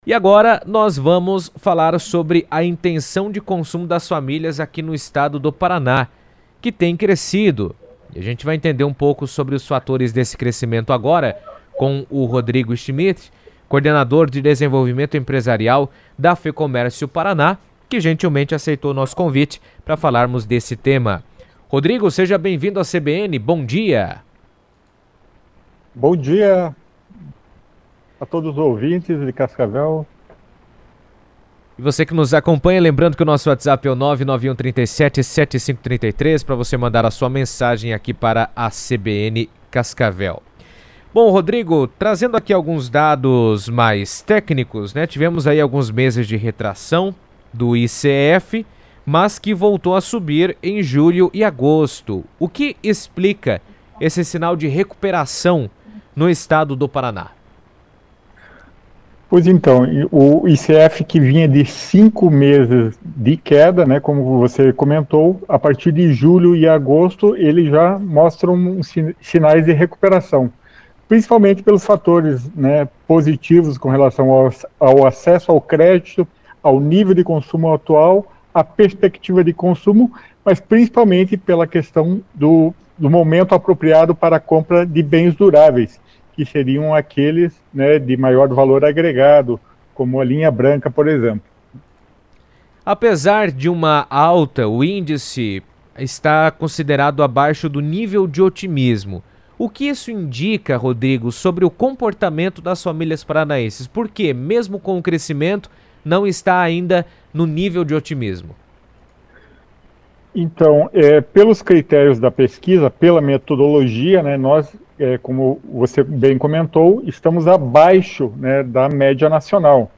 falou sobre o assunto na CBN Cascavel, comentando os números e suas implicações para o mercado local.